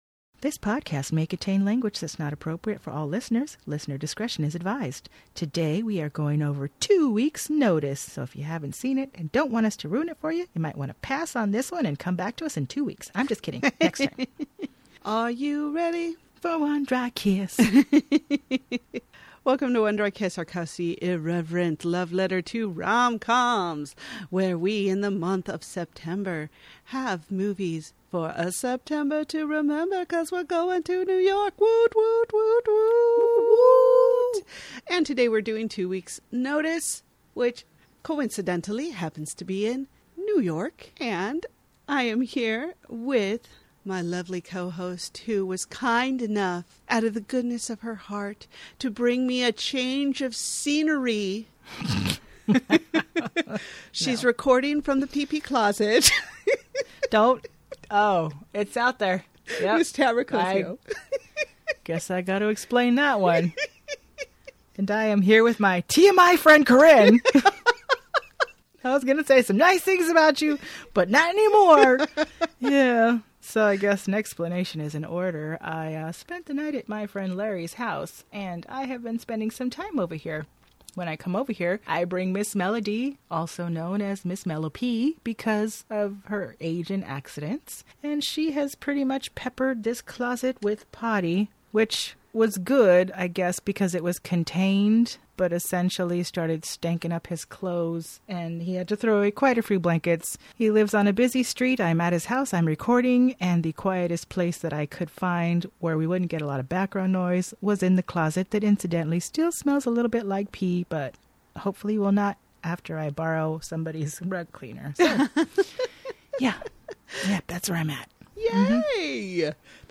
In this episode we discuss how George is an HR liability waiting to happen, ponder if Lucy had an eating disorder (seriously did she?), and give spectacular advice like putting your phone on vibrate and in your underpants for some added enjoyment to your day. We are broadcasting from the pee pee closet which stinks (a little) but this episode doesn't and we hope you agree